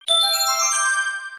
1-Up Mushroom sound effect from Super Mario Galaxy
SMG_1-up_SFX.mp3